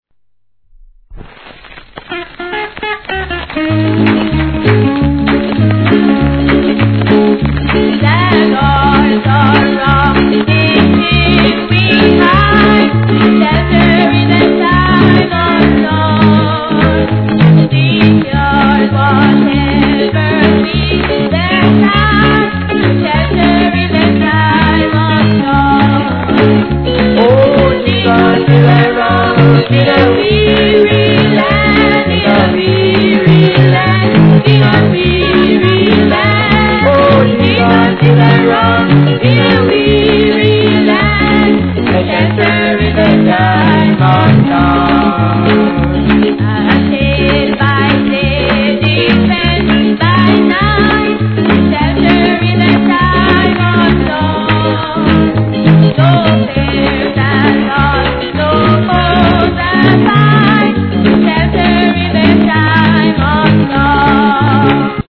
C / ノイズ入ります
1. REGGAE